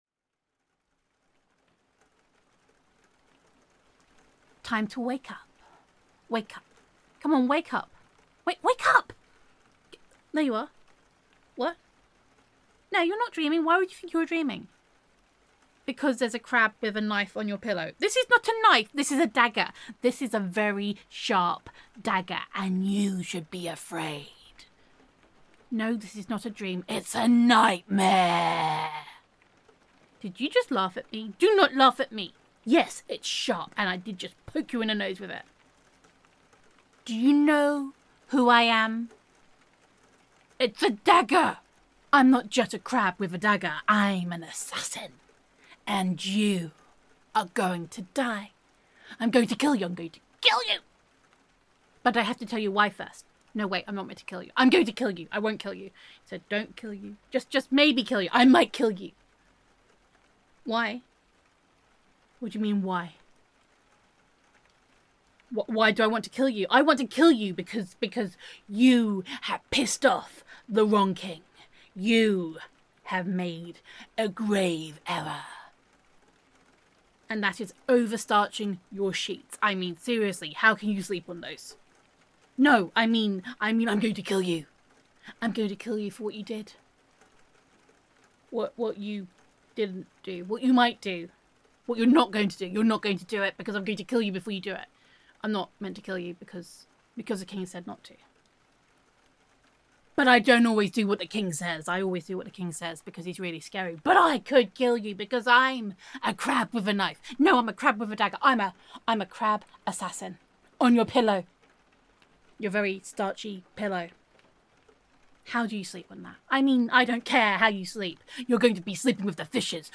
[F4A]